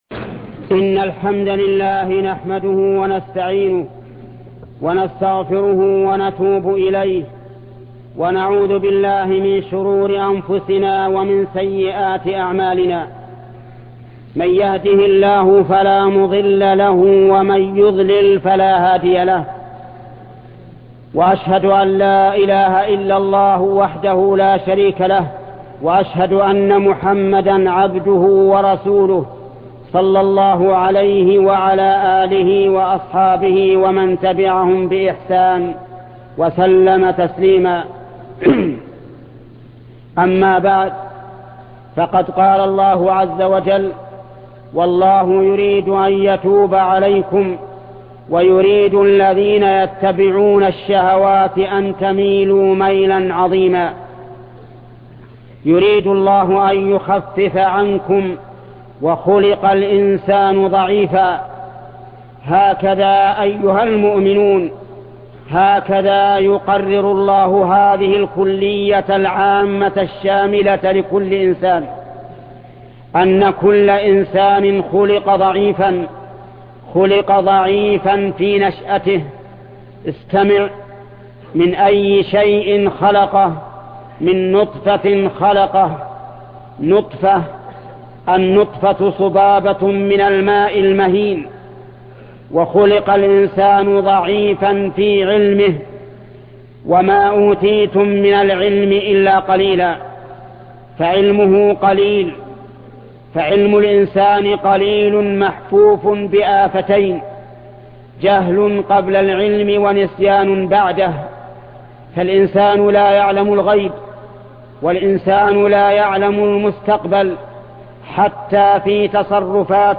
خطبة بعض أنواع الربا -وتحريم تفاضل الذهب وتأخير القبض بأمر الله ورسوله وأولي الأمر الشيخ محمد بن صالح العثيمين